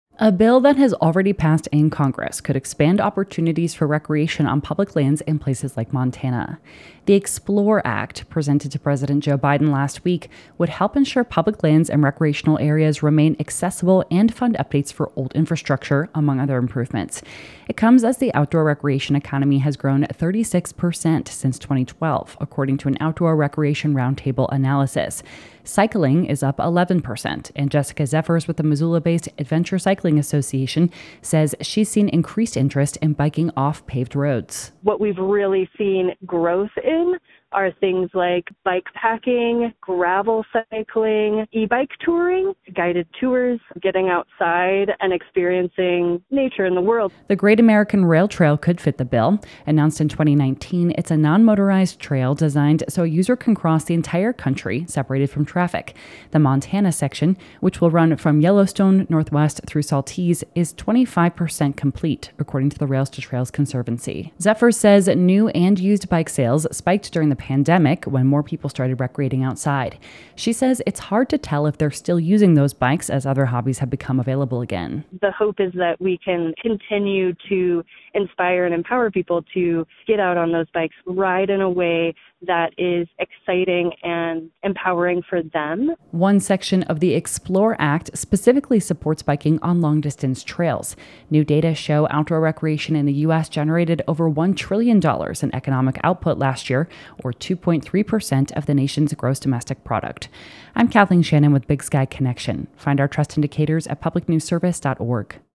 Producer